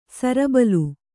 ♪ sarabalu